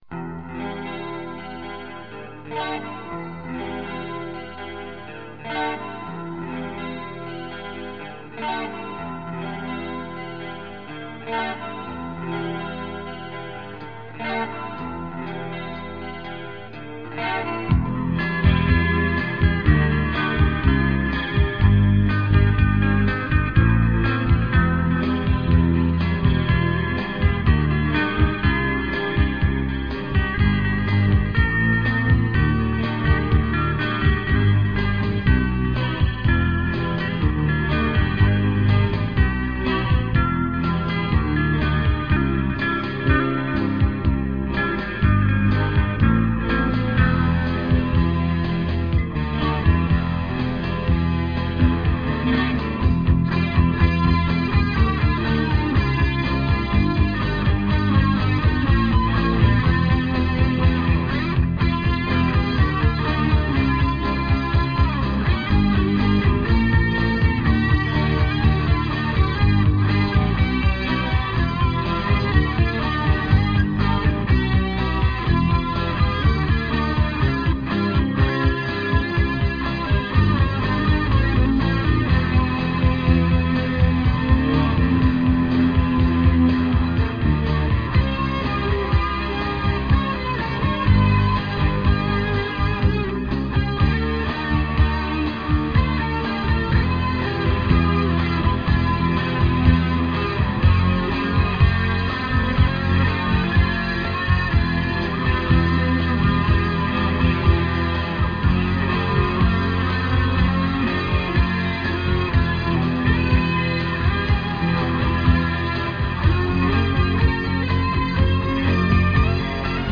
Un remix MP3 du thème